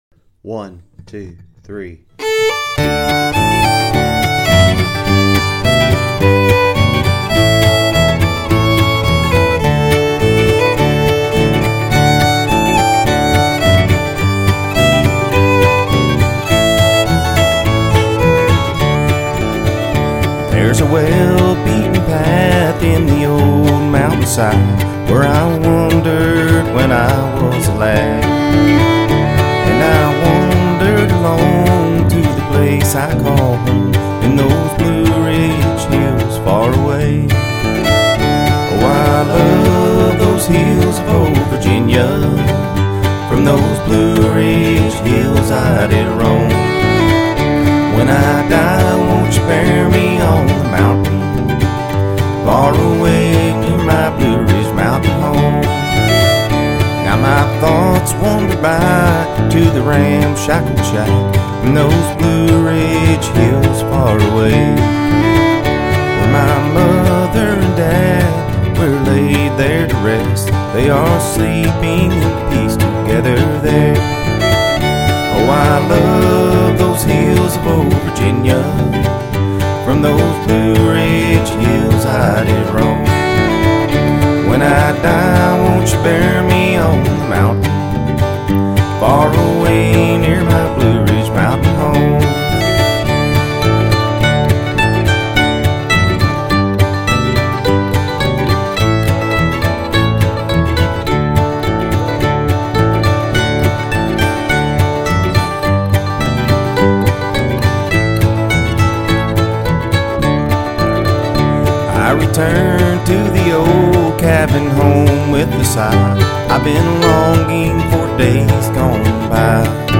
Blueridge-Mountian-Home-Key-D.mp3